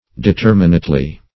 determinately - definition of determinately - synonyms, pronunciation, spelling from Free Dictionary
Determinately \De*ter"mi*nate*ly\, adv.